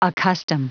Prononciation du mot accustom en anglais (fichier audio)
Prononciation du mot : accustom